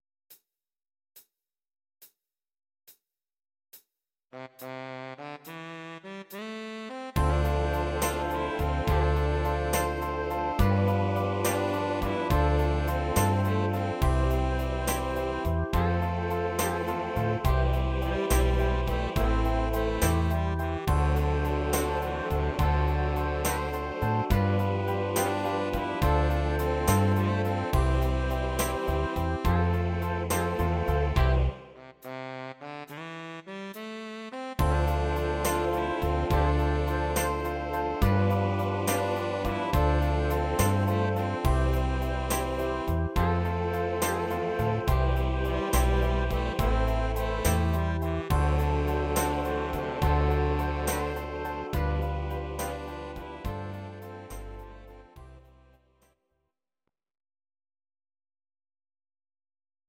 Audio Recordings based on Midi-files
Pop, Oldies, 1950s